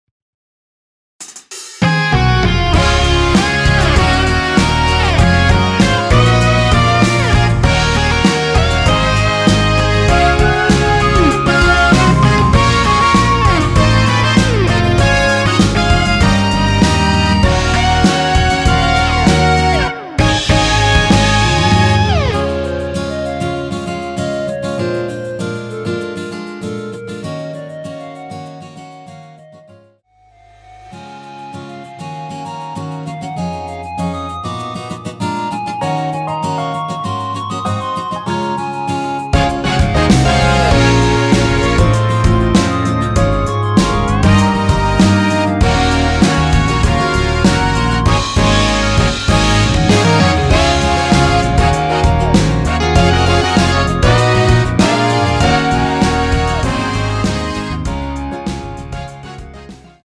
원키에서(+1)올린 멜로디 포함된 MR입니다.(미리듣기 확인)
Db
◈ 곡명 옆 (-1)은 반음 내림, (+1)은 반음 올림 입니다.
앞부분30초, 뒷부분30초씩 편집해서 올려 드리고 있습니다.
중간에 음이 끈어지고 다시 나오는 이유는